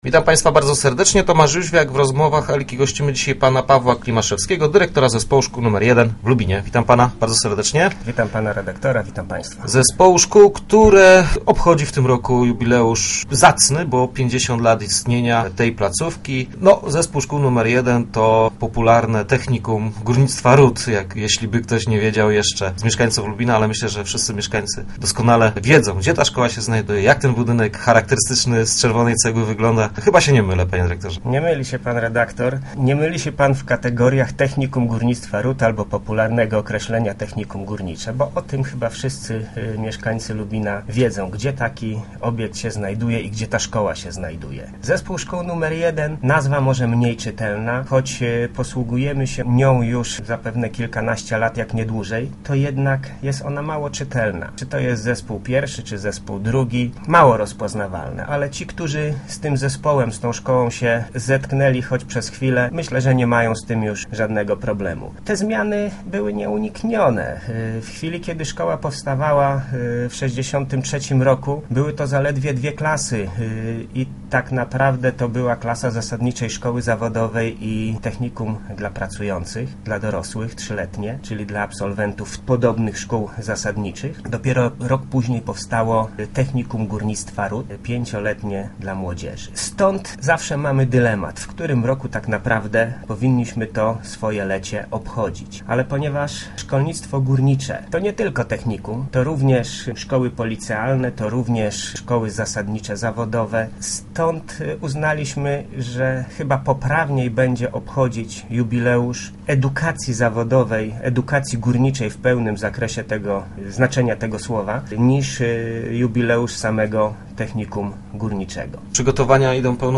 Rozmowy Elki